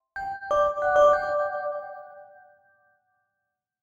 Simple Clean Logo
ad alert announcement bell brand bright catchy clean sound effect free sound royalty free Animals